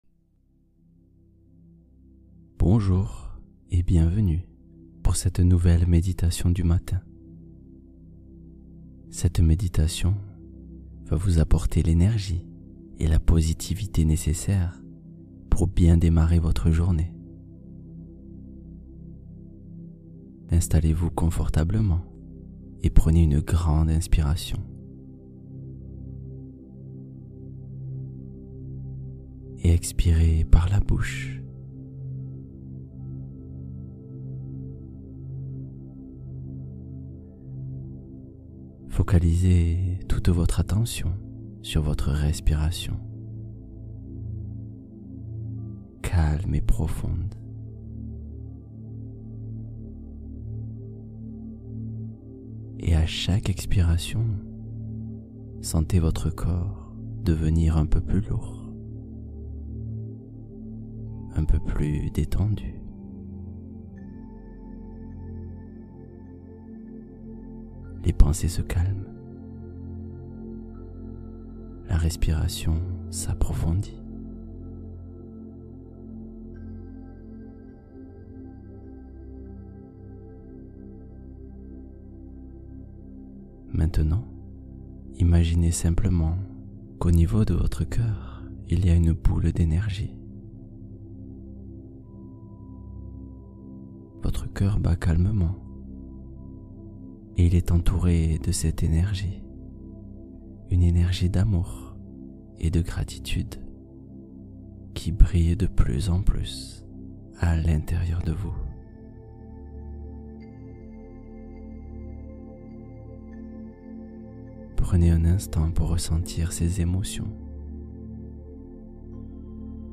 S’endormir naturellement — Méditation pour entrer doucement dans la nuit